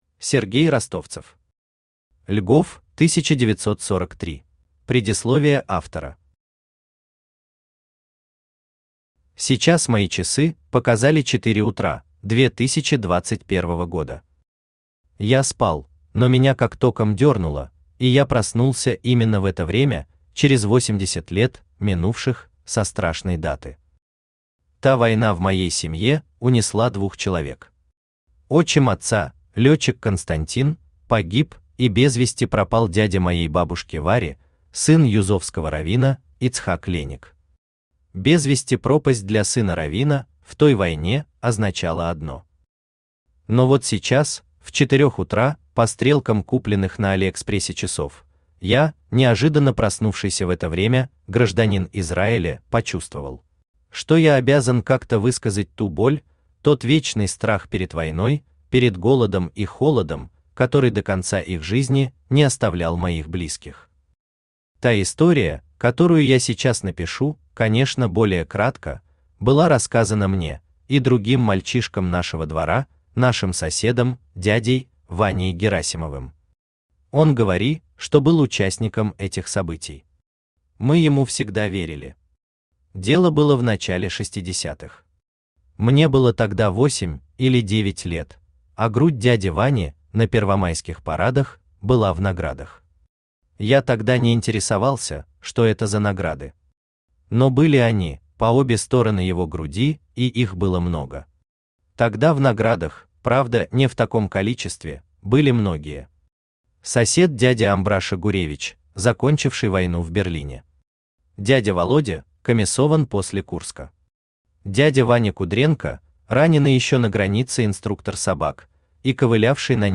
Аудиокнига Льгов 1943 | Библиотека аудиокниг
Aудиокнига Льгов 1943 Автор Сергей Юрьевич Ростовцев Читает аудиокнигу Авточтец ЛитРес.